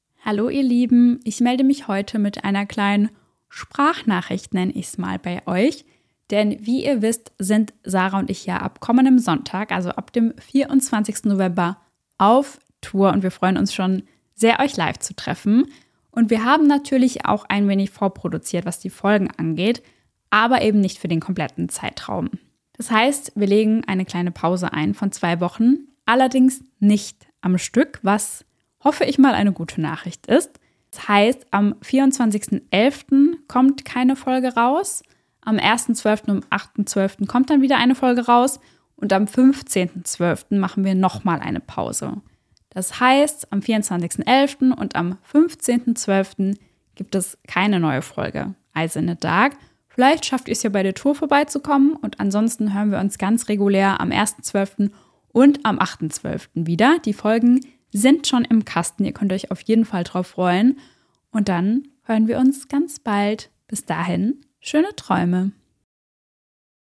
Sprachnachricht